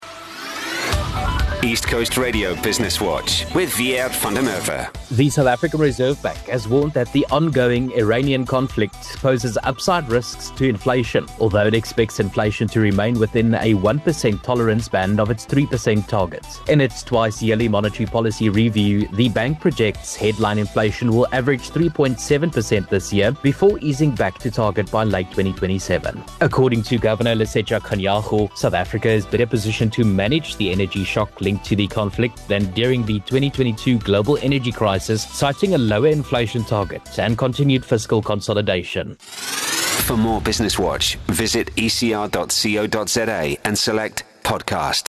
Business Watch is a daily feature on East Coast Radio - on-air at 06:40 every weekday.
Genres: Business, Business News, News